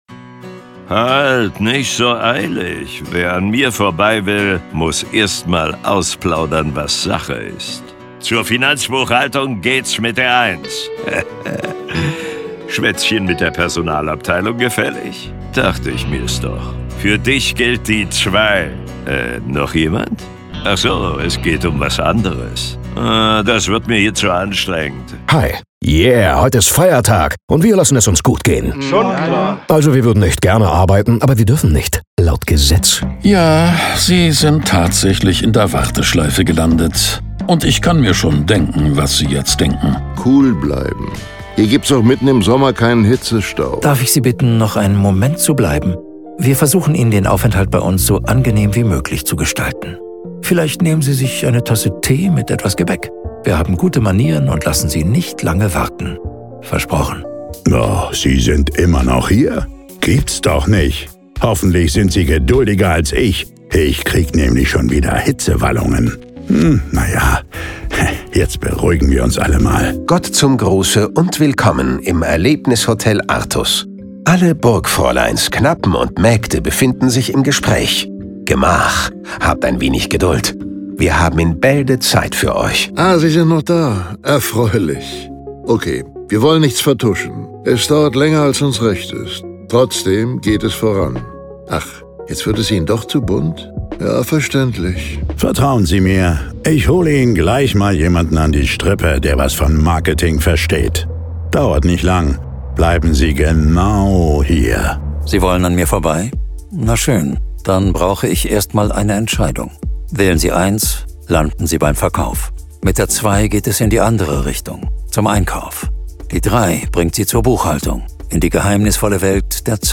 Exklusiv und hochwertig für besondere Marken